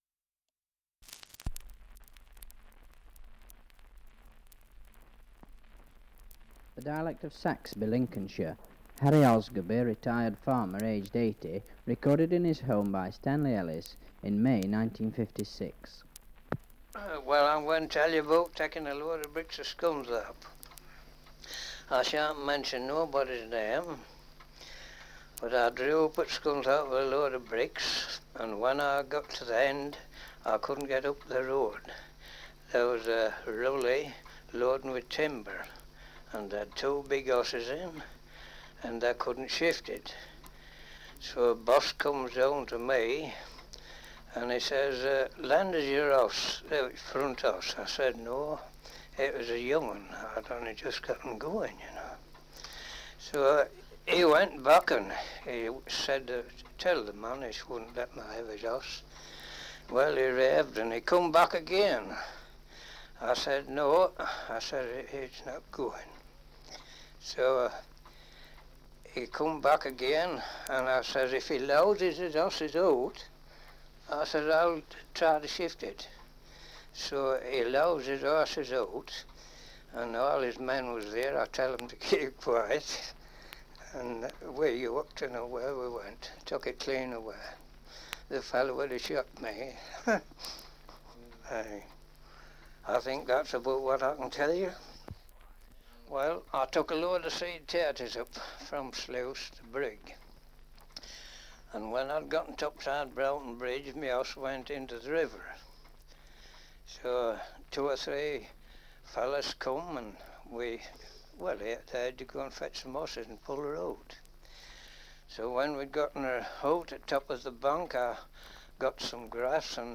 Survey of English Dialects recording in Saxby All Saints, Lincolnshire
78 r.p.m., cellulose nitrate on aluminium